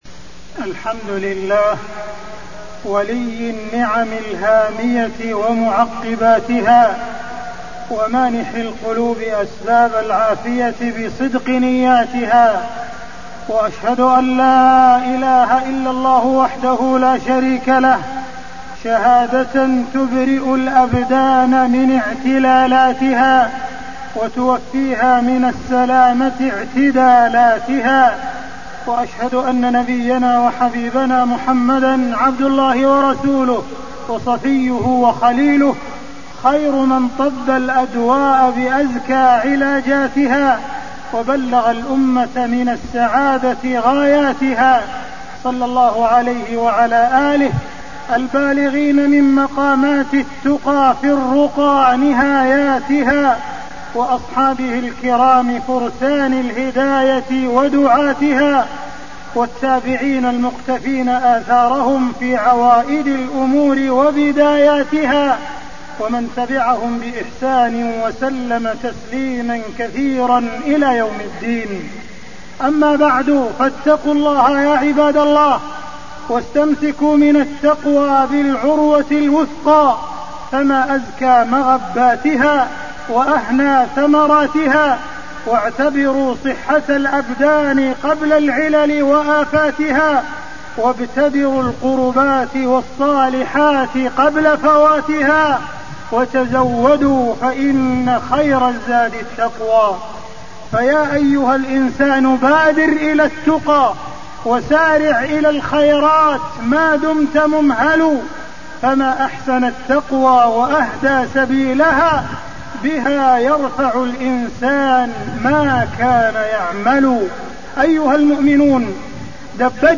تاريخ النشر ١٠ صفر ١٤٣٢ هـ المكان: المسجد الحرام الشيخ: معالي الشيخ أ.د. عبدالرحمن بن عبدالعزيز السديس معالي الشيخ أ.د. عبدالرحمن بن عبدالعزيز السديس الرقية الشرعية أحكام وآداب